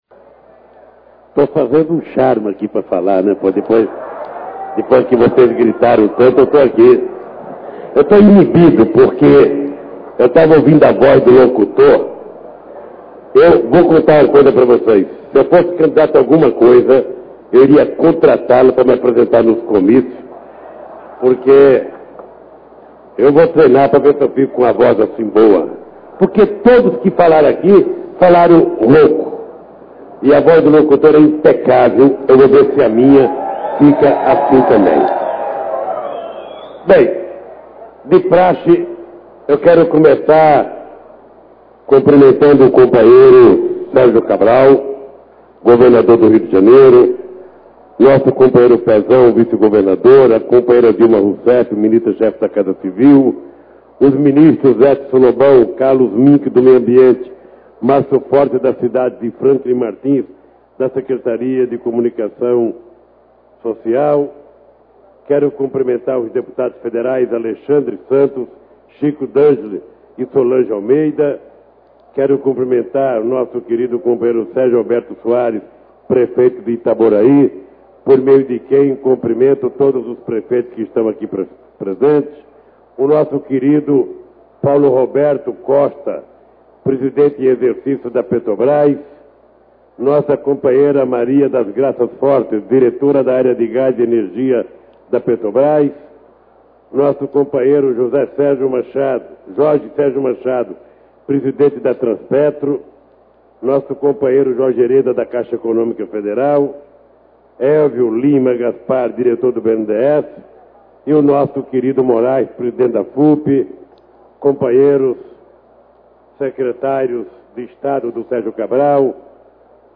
Rio de Janeiro-RJ